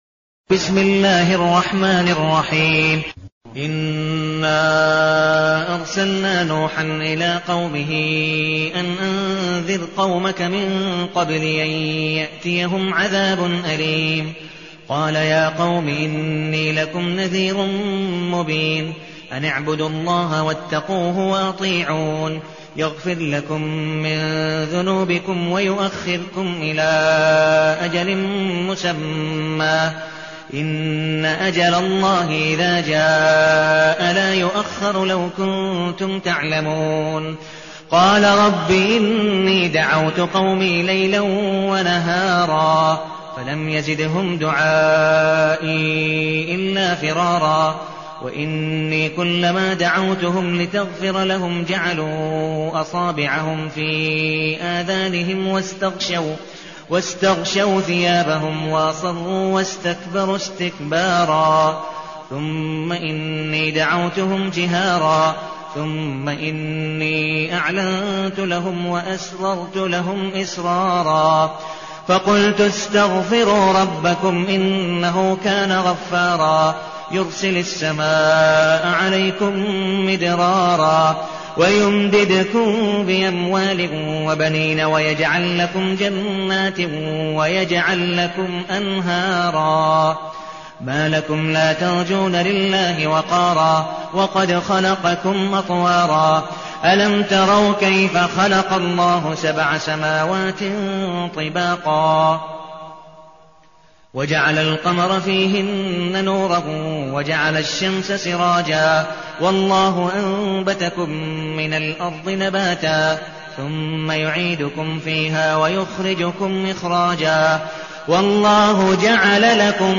المكان: المسجد النبوي الشيخ: عبدالودود بن مقبول حنيف عبدالودود بن مقبول حنيف نوح